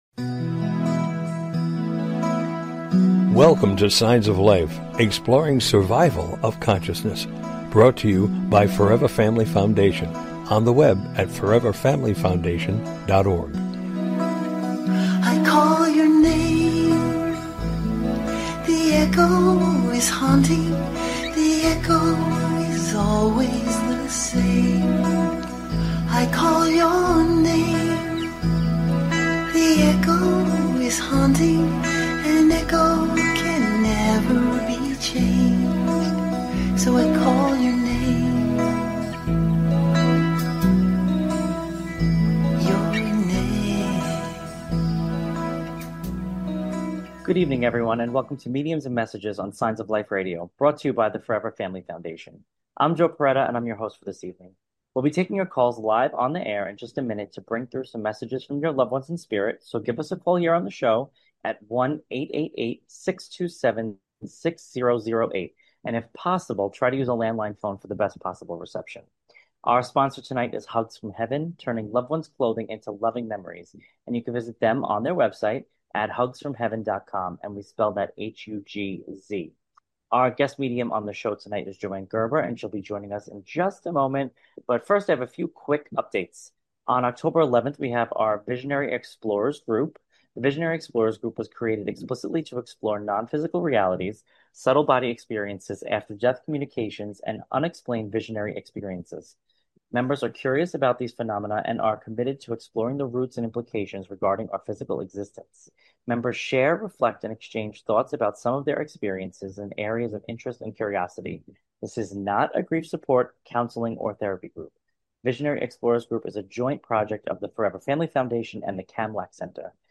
Signs of Life Radio Show is a unique radio show dedicated to the exploration of Life After Death!